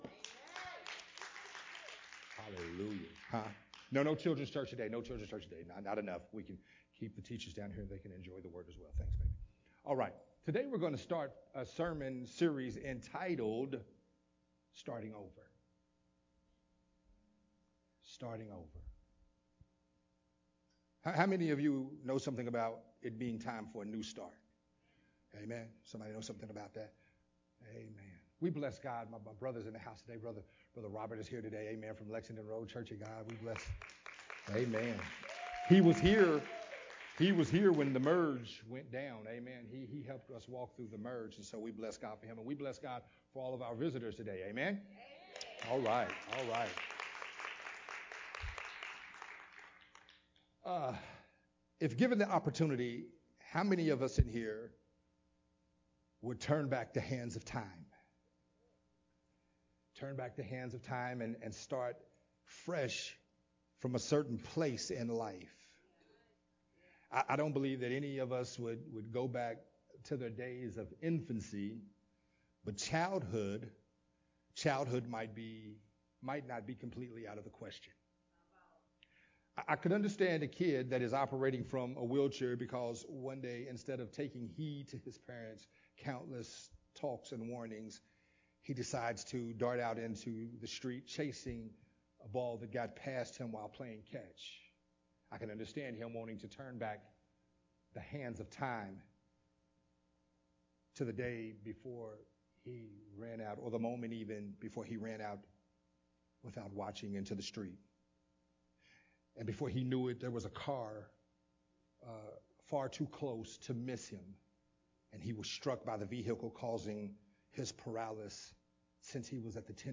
recorded at Unity Worship Center on October 24th. 2021.
sermon